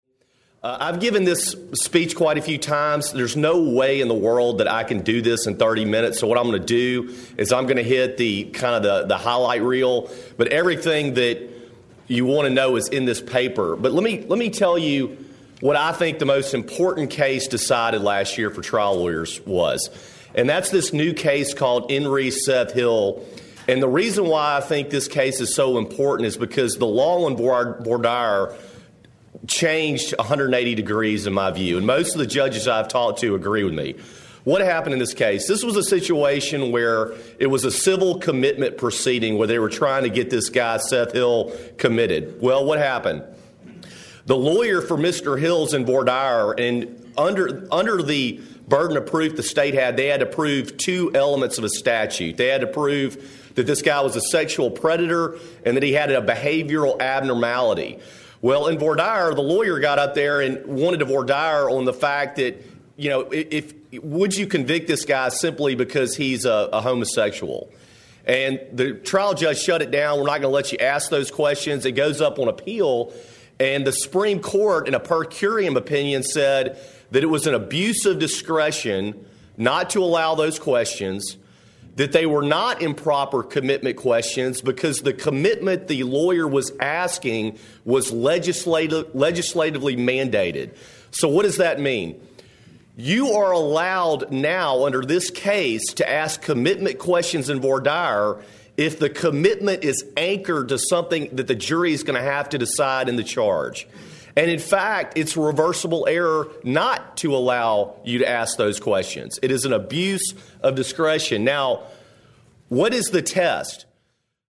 Originally presented: Oct 2012 Page Keeton Civil Litigation Conference